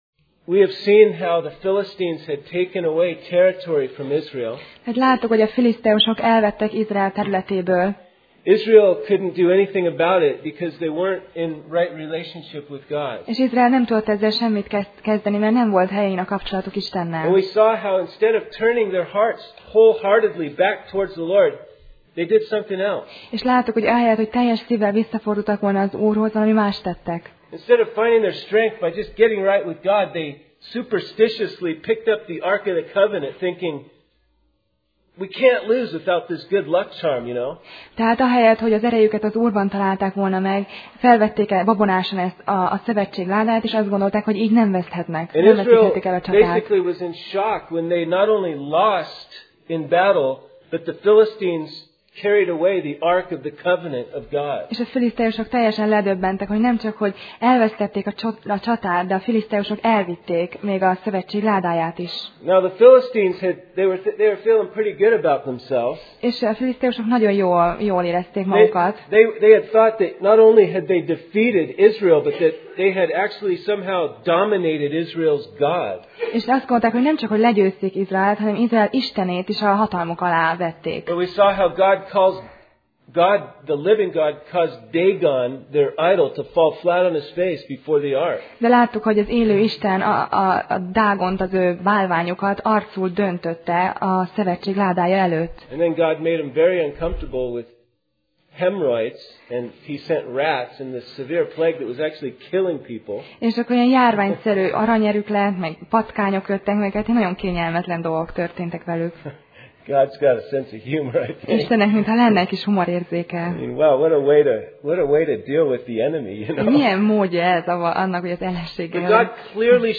1Sámuel Passage: 1Sámuel (1Samuel) 7:1-17 Alkalom: Szerda Este